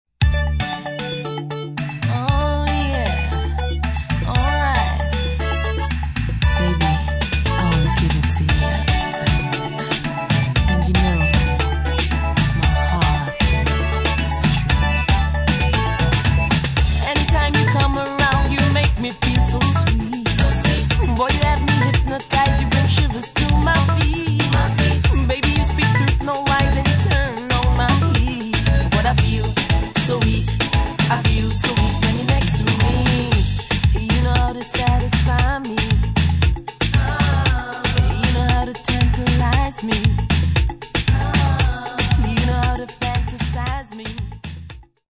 Reviews | Sacred | Soca | Steelband | Videos